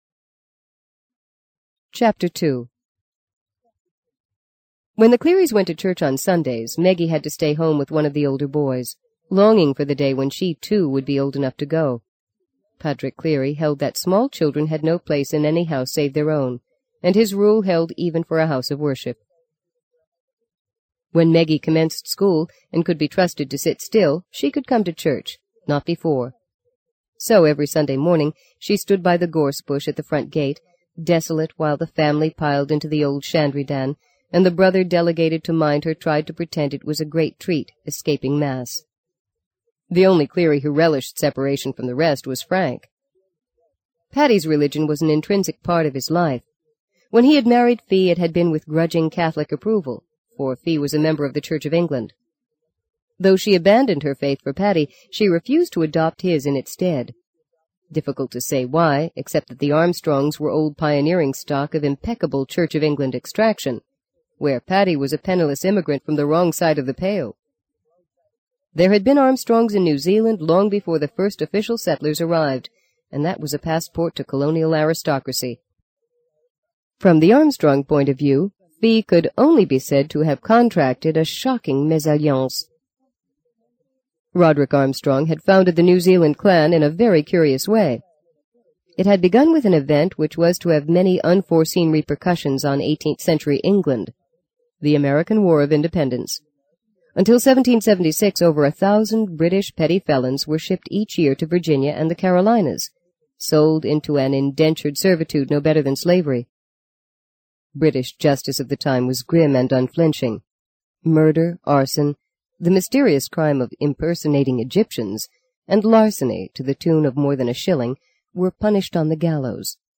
在线英语听力室【荆棘鸟】第二章 01的听力文件下载,荆棘鸟—双语有声读物—听力教程—英语听力—在线英语听力室